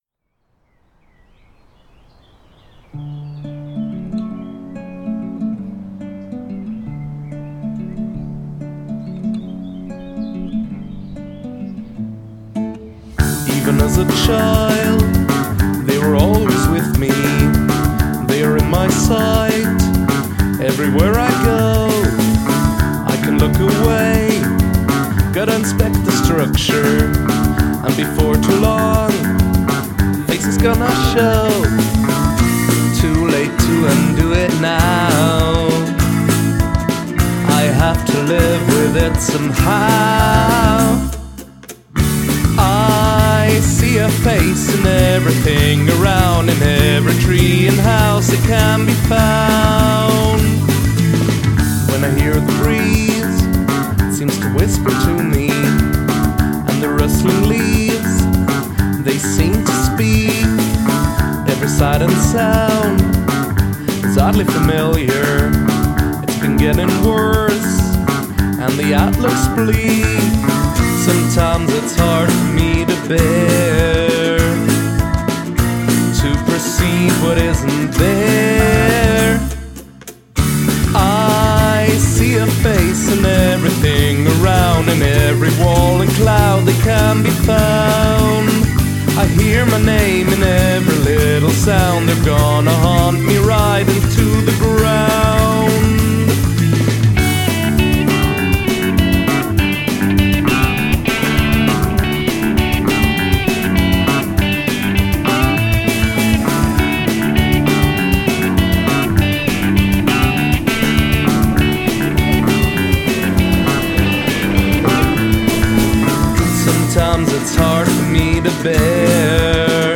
Use of field recording
I like the organ part a lot.